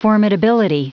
Prononciation du mot formidability en anglais (fichier audio)
Prononciation du mot : formidability